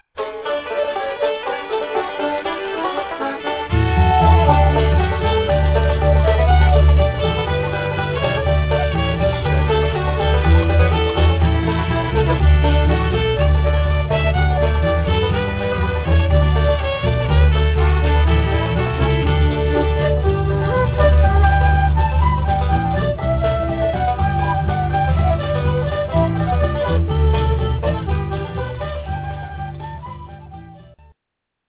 Audio CD with 13 dance length tracks for 14 dances